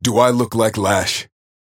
Warden voice line - "Do I look like Lash?"